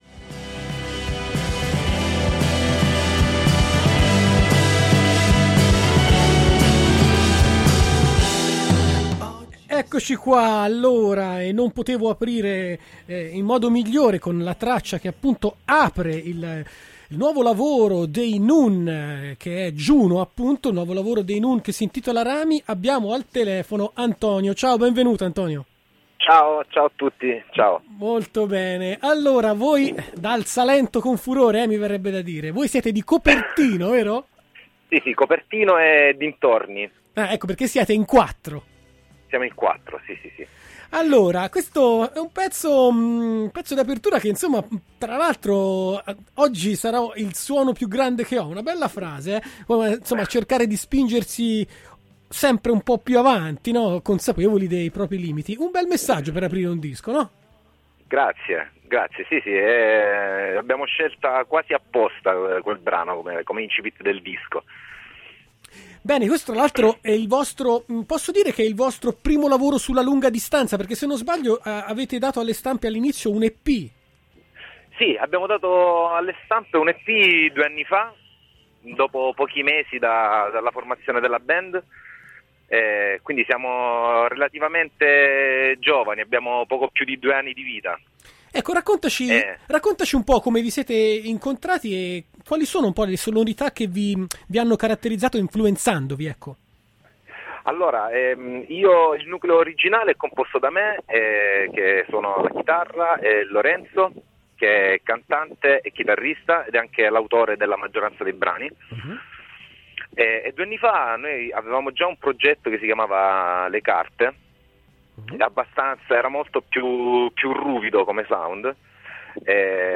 Intervista ai Noon al Matinée del 09.05.2017 | Radio Città Aperta
intervista-noon.mp3